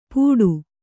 ♪ pūḍu